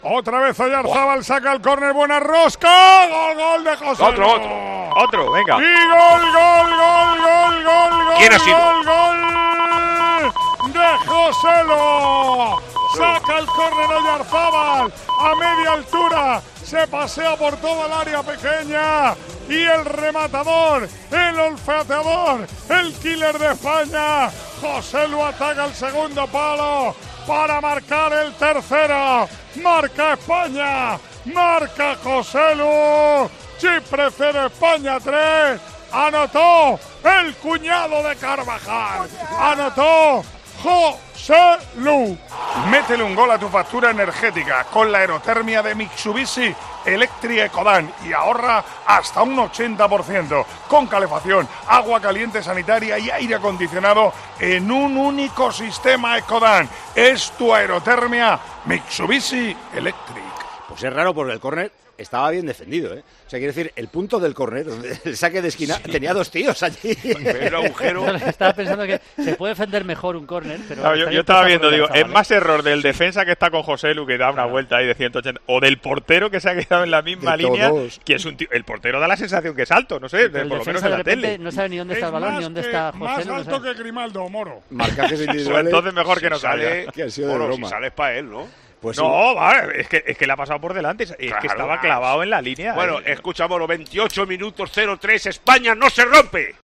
Gol de Joselu (Chipre, 0 - España, 3)
ASÍ TE HEMOS CONTADO EN COPE LA VICTORIA DE ESPAÑA EN CHIPRE